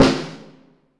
French! Snare.wav